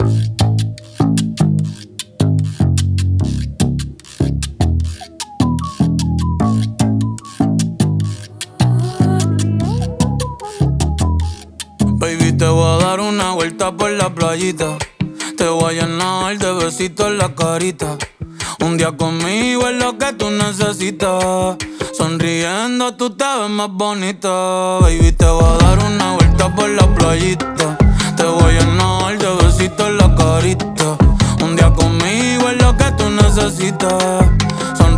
# Latin